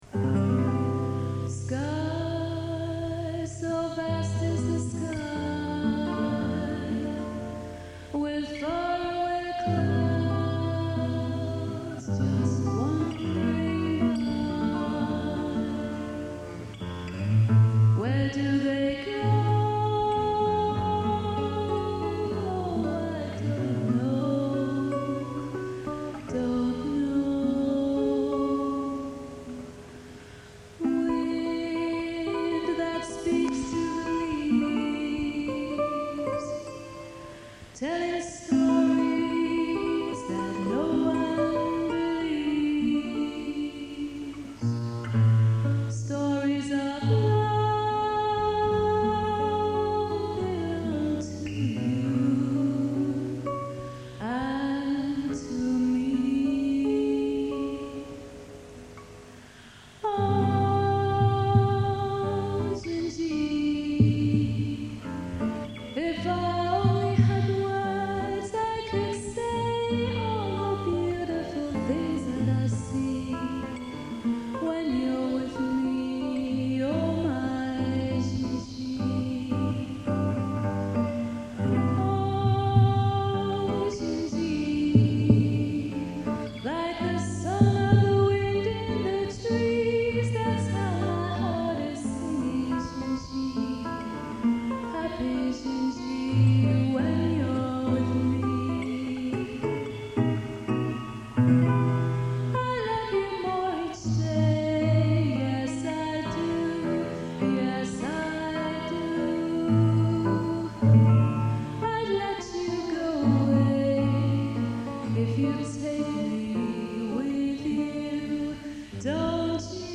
Classical guitar pieces